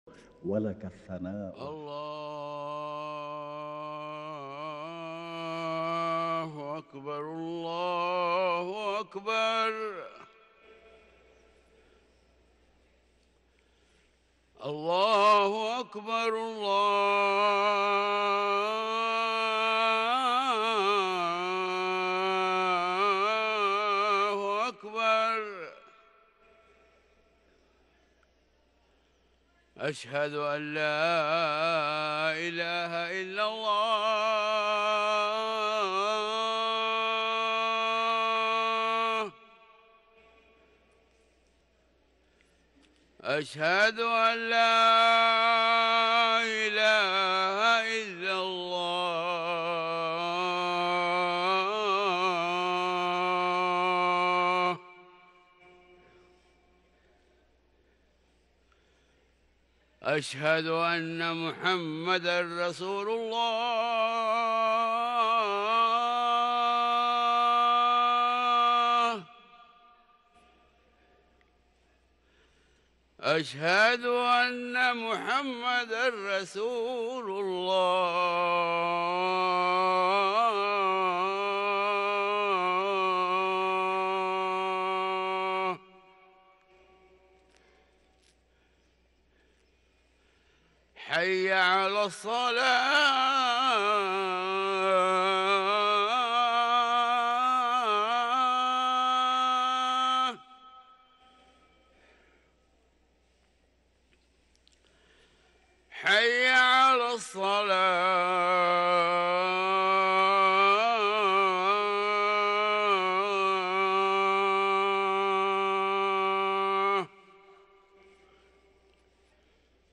اذان الفجر